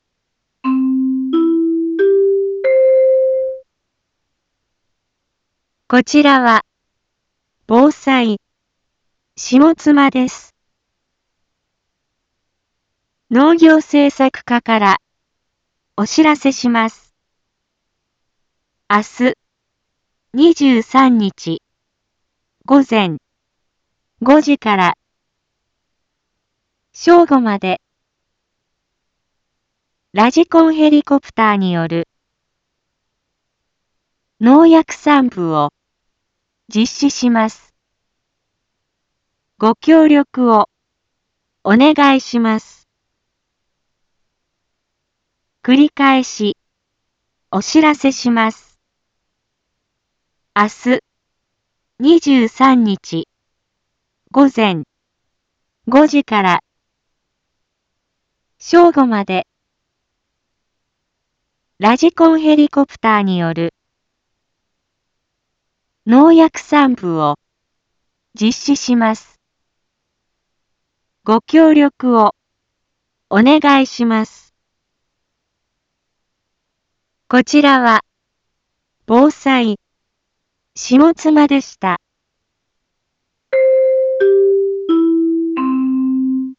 一般放送情報
Back Home 一般放送情報 音声放送 再生 一般放送情報 登録日時：2024-04-22 12:31:27 タイトル：麦のラジコンヘリによる防除 インフォメーション：こちらは、ぼうさい、しもつまです。